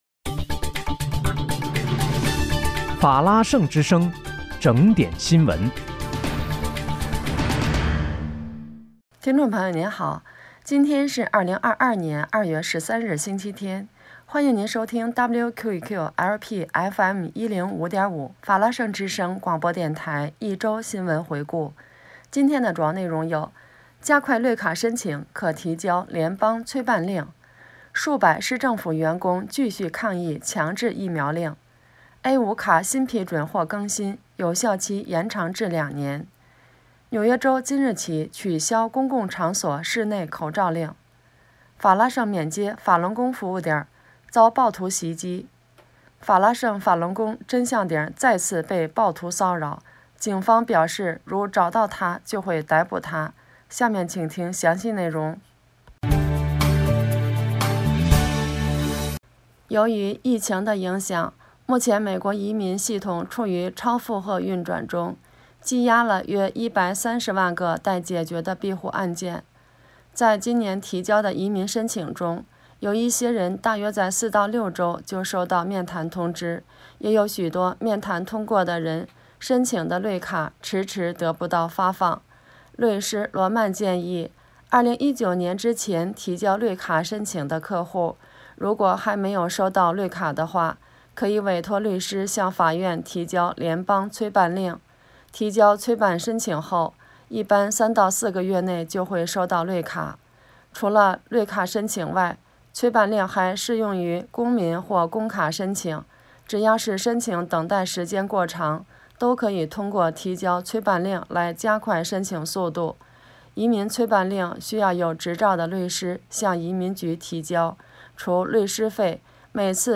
2月13日（星期日）一周新闻回顾